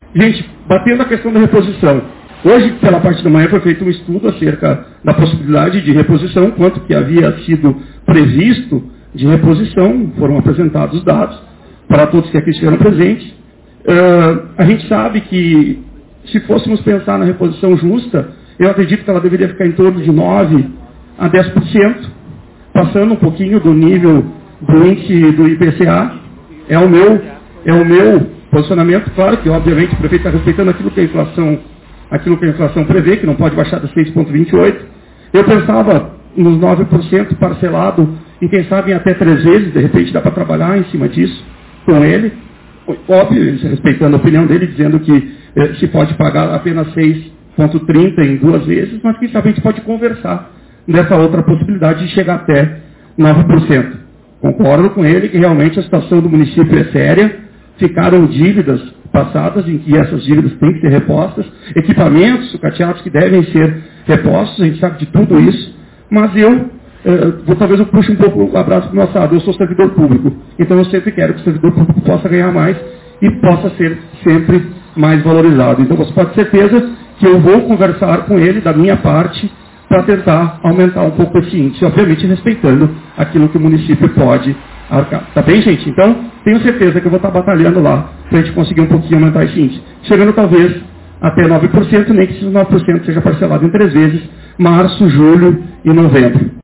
Em assembleia extraordinária realizada na noite desta segunda-feira, 20, os servidores públicos municipais receberam uma nova proposta da Administração de Frederico Westphalen mediante o reajuste salarial da categoria que possui data-base prevista para este mês de fevereiro.
O vereador, Olivério de Vargas Rosado, destacou durante seu pronunciamento que a categoria deve buscar um número maior: